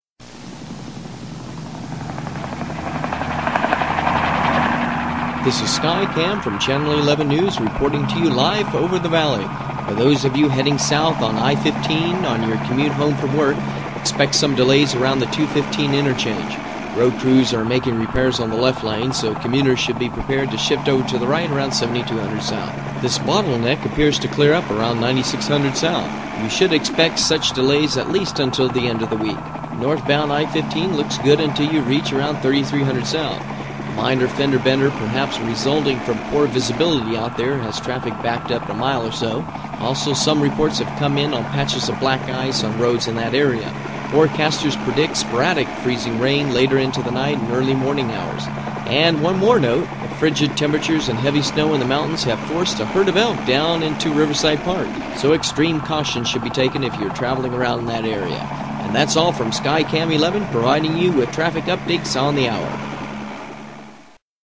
Traffic-Report.mp3